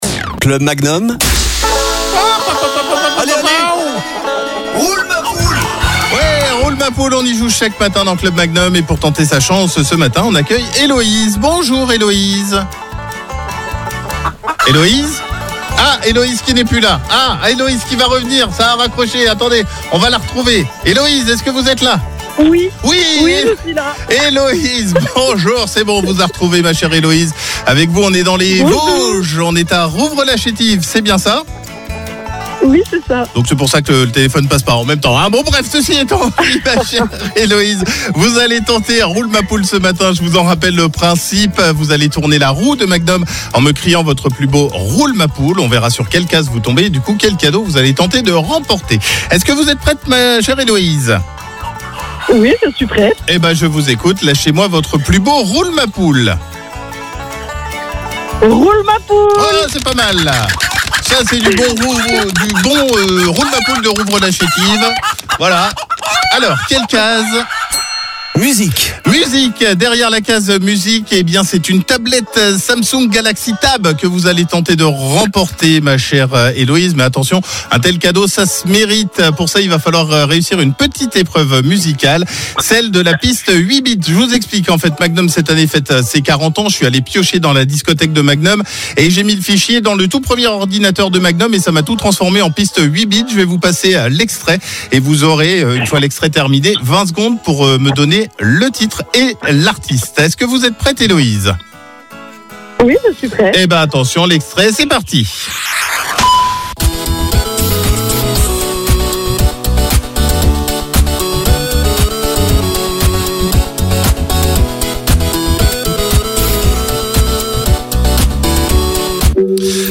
Tournez la roue en criant « Roule ma poule » , plus vous criez fort, plus la roue va tourner.